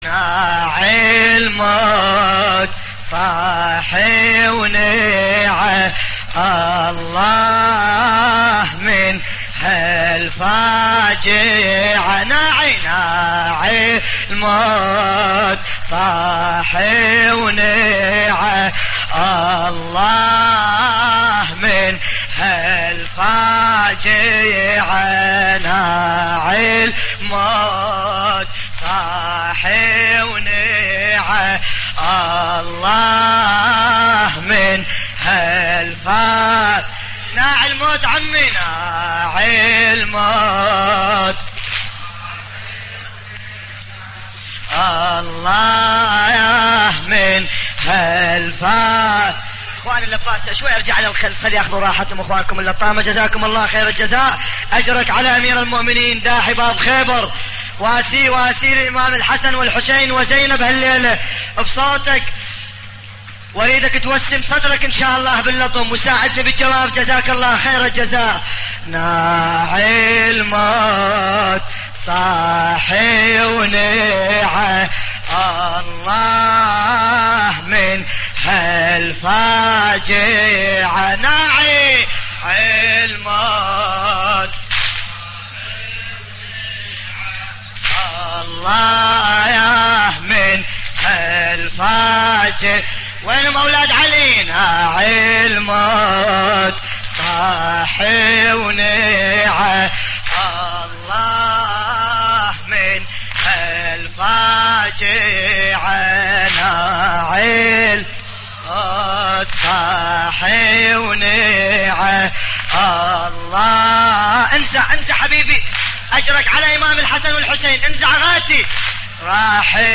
تحميل : ناعي الموت صاح ونعى الله من هالفاجعة / الرادود باسم الكربلائي / اللطميات الحسينية / موقع يا حسين